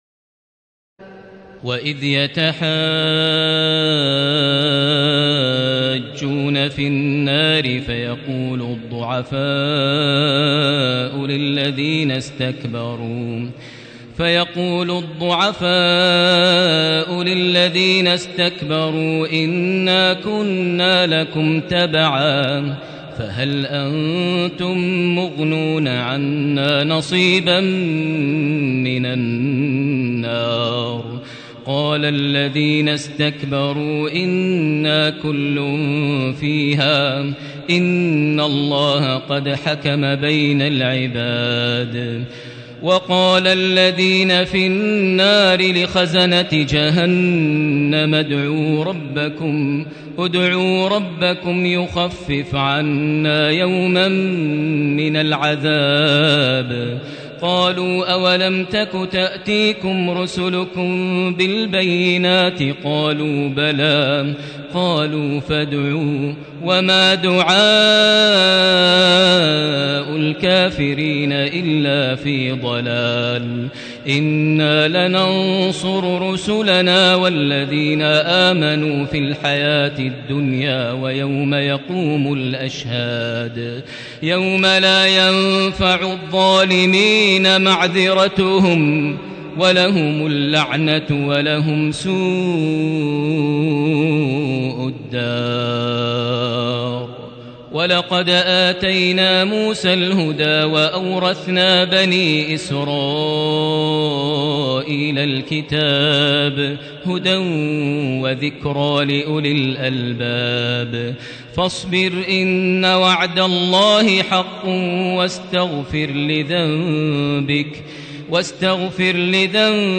تراويح ليلة 23 رمضان 1437هـ من سور غافر (47-85) وفصلت (1-46) Taraweeh 23 st night Ramadan 1437H from Surah Ghaafir and Fussilat > تراويح الحرم المكي عام 1437 🕋 > التراويح - تلاوات الحرمين